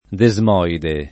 [ de @ m 0 ide ]